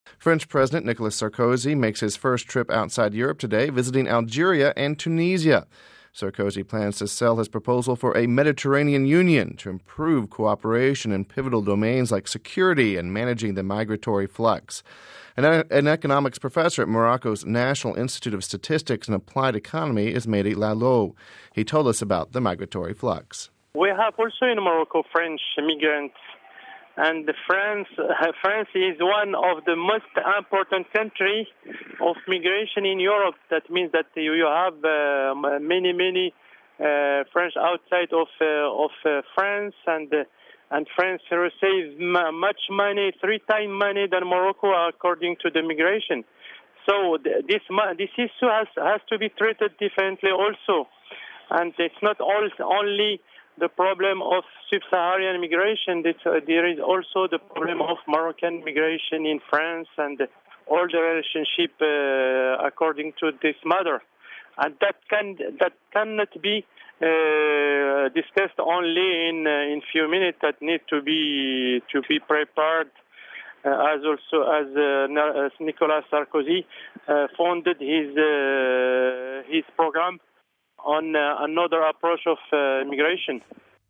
Home Archivio 2007-07-10 08:55:40 French President Goes to North Africa (10 July 07 - RV) French President Nicolas Sarkozy will visit Algeria and Tunisia in his first visit to a non-European country since his election. We have this report...